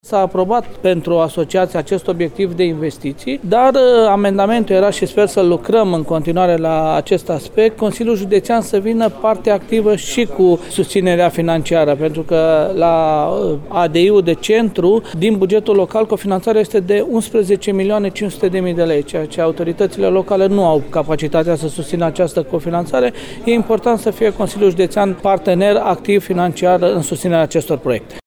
Primăriile care nu vor putea asigura cofinanțarea ar trebui să primească bani de la CJ Timiș este de părere consilierul județean, Călin Dobra.